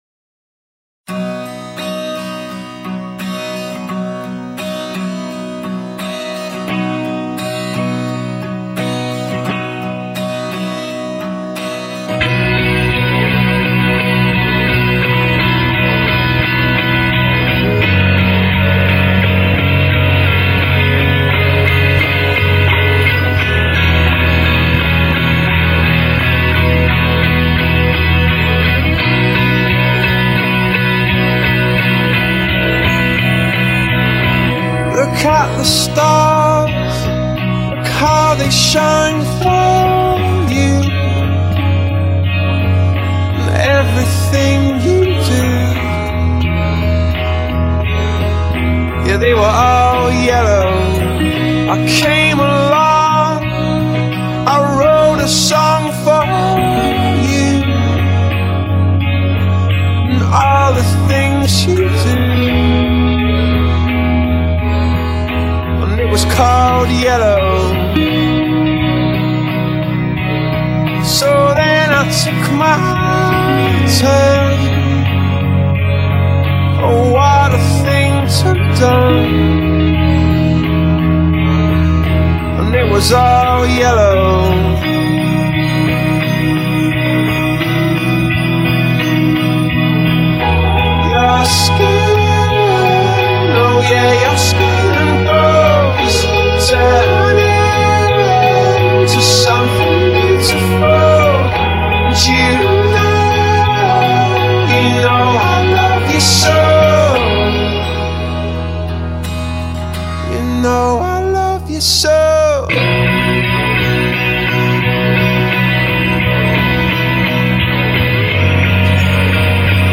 -动态鼓谱+无鼓伴奏+纯鼓声+节拍器
'已收藏' : '收藏'}} 4/4 初级 动态鼓谱 无鼓伴奏 欧美